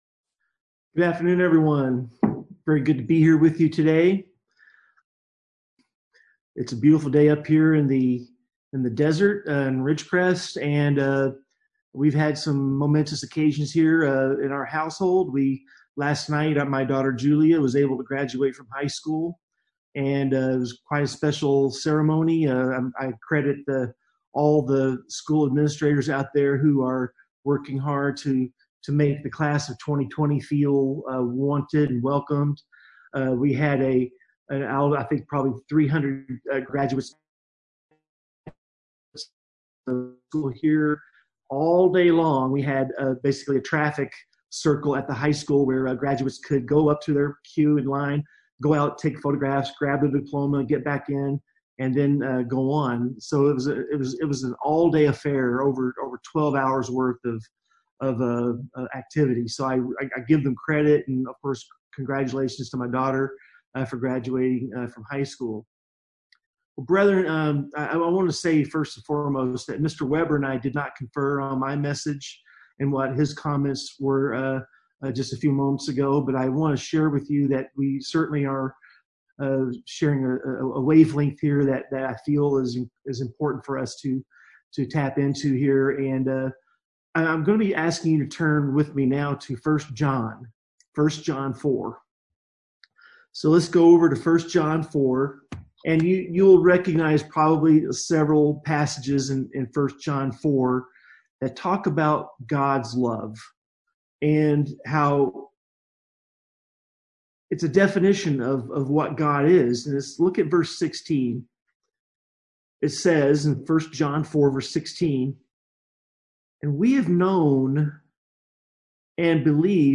Given in San Diego, CA